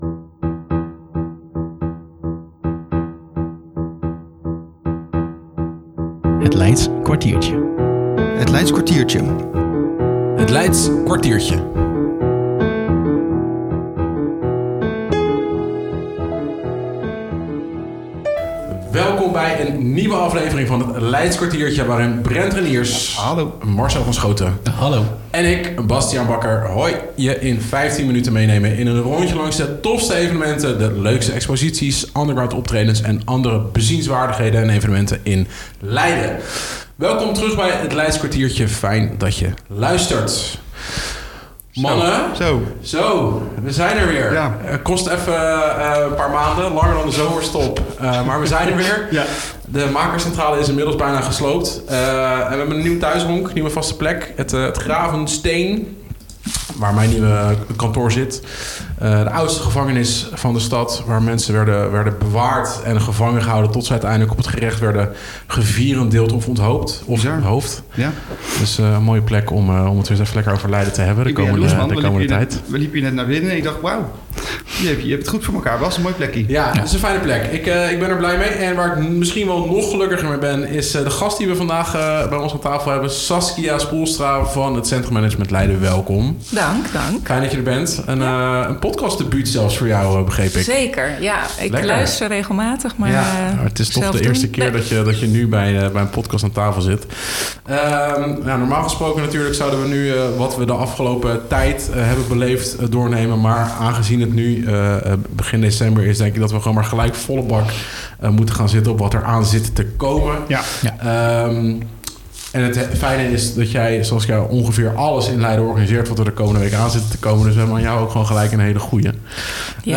Gelukkig niet in een van de kerkers maar gewoon in prachtig kantoor.